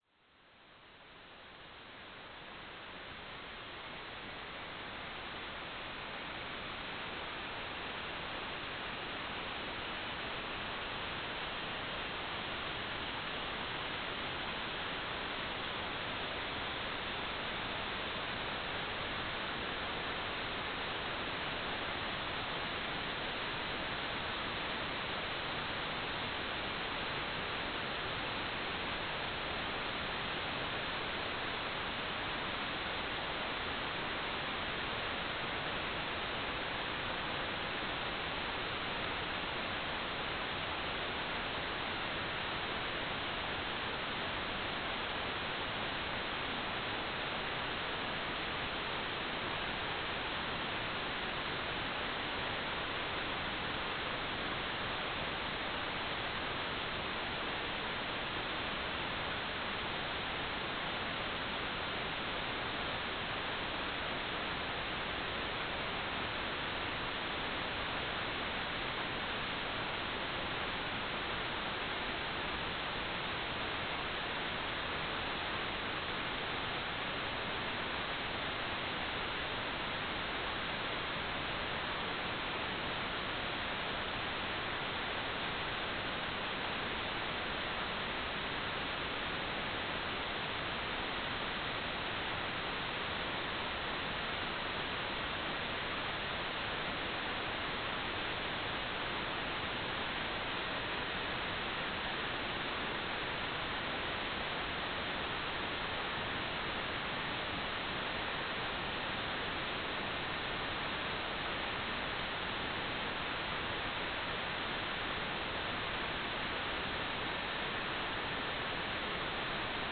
"transmitter_description": "CW TLM",
"transmitter_mode": "CW",